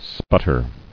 [sput·ter]